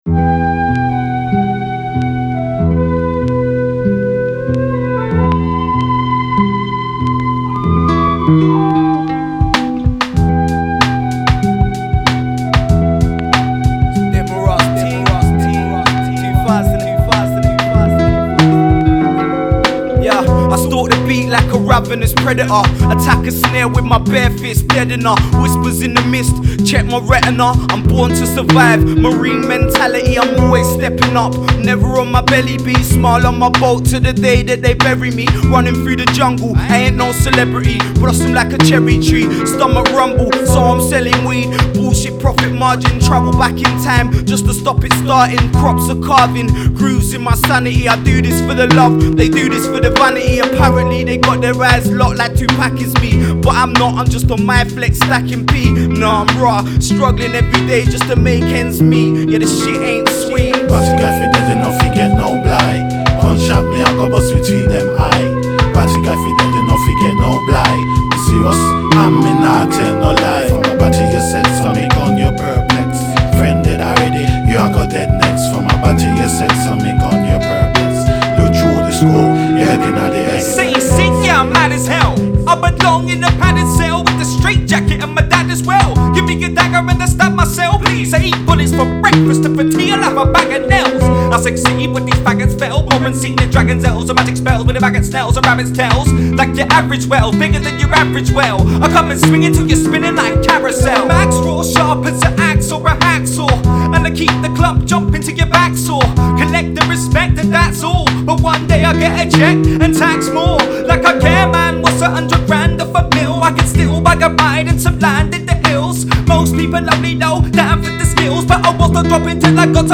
underground album